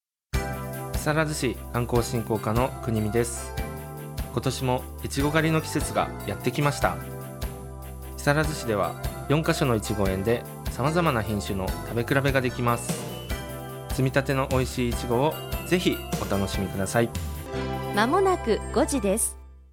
木更津の魅力をPRするCMを放送しています！
出演者：木更津市観光振興課